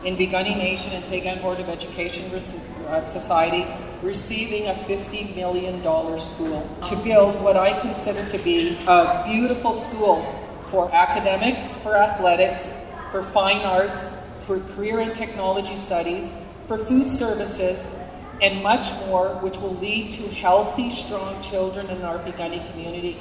at the sod-turning ceremony